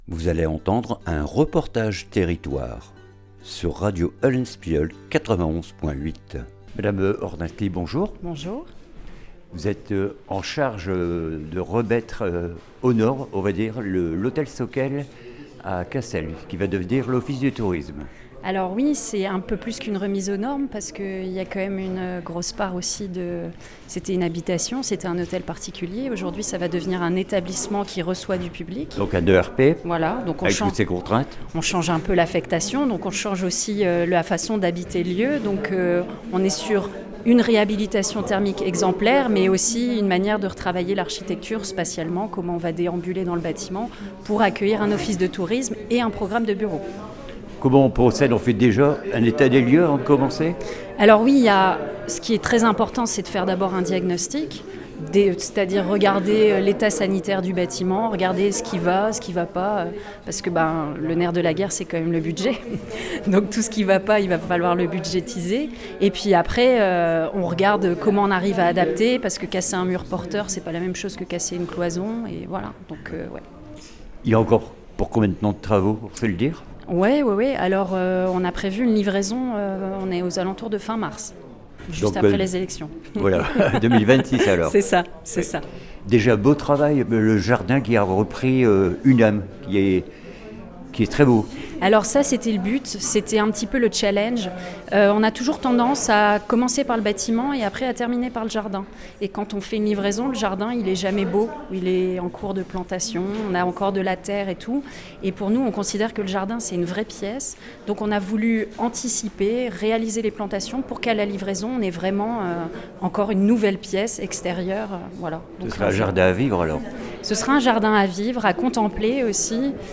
REPORTAGE TERRITOIRE HOTEL SOCKEEL CASSEL